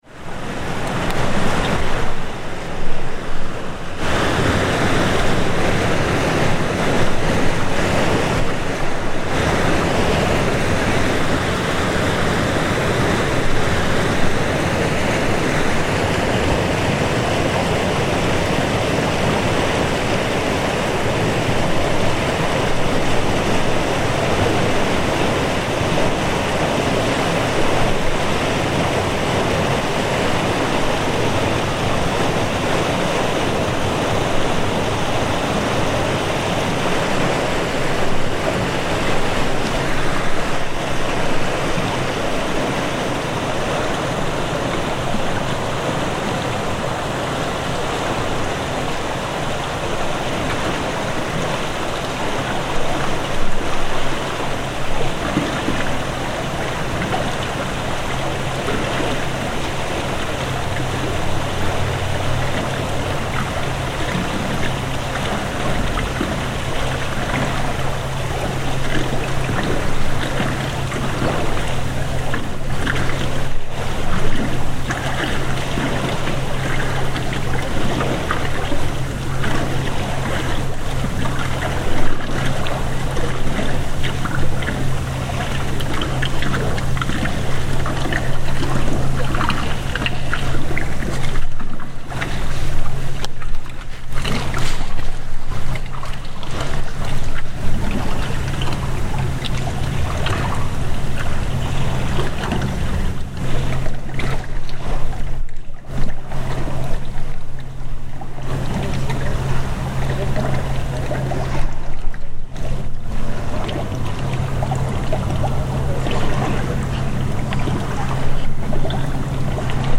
Huge lock gates discharging water
Huge lock gates in Bremerhaven, Germany discharge large volumes of water, then slowly decrease in intensity until the flow ends and becomes a more gentle dripping.